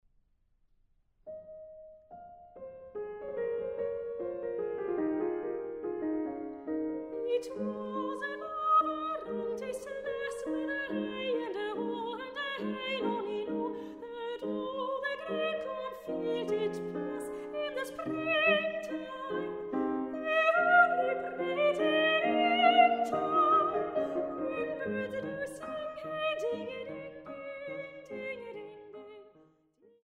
Englische Liebeslieder aus drei Jahrhunderten
Sopran
Laute